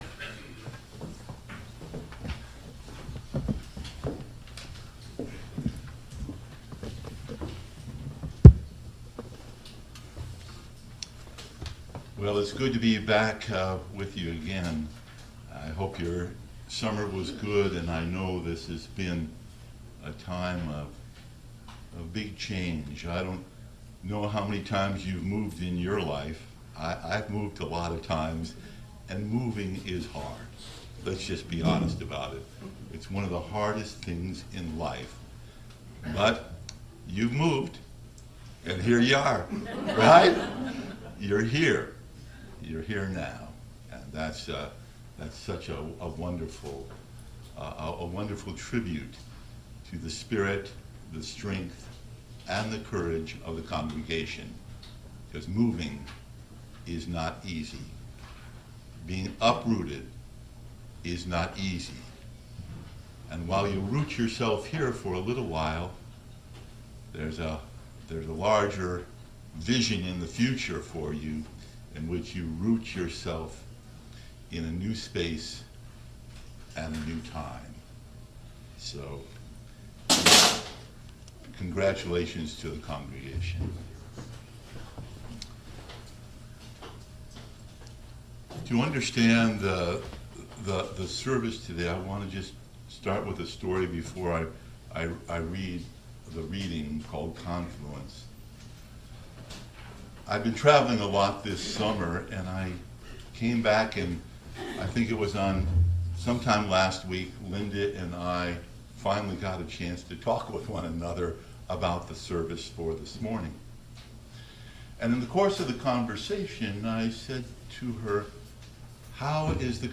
Today we celebrate our annual “water communion” service, celebrating how, when we join together our hopes and cares, something beautiful emerges. All are invited to bring a small vial of waters to contribute to the ritual.